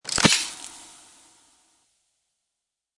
Spit Sound Effect Download: Instant Soundboard Button